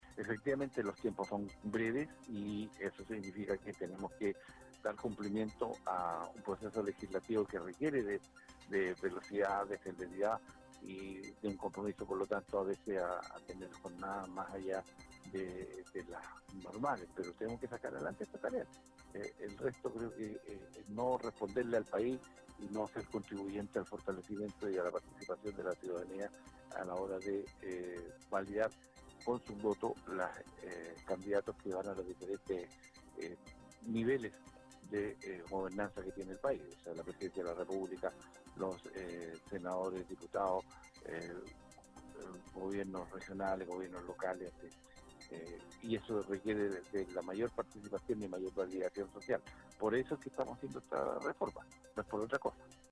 En entrevista con Radio UdeC, Saavedra advirtió que, sin sanciones claras, la obligatoriedad del sufragio queda en entredicho.